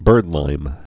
(bûrdlīm)